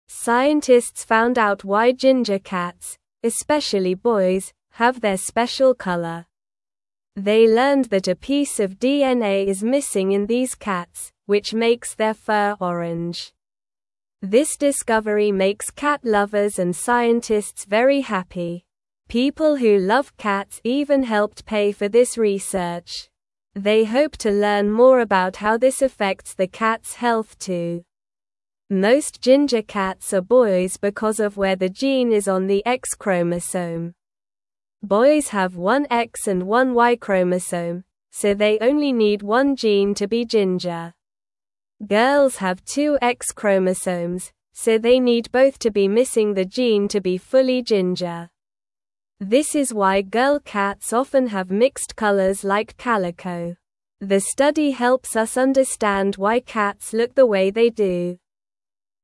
Slow
English-Newsroom-Beginner-SLOW-Reading-Why-Ginger-Cats-Are-Mostly-Boys-and-Orange.mp3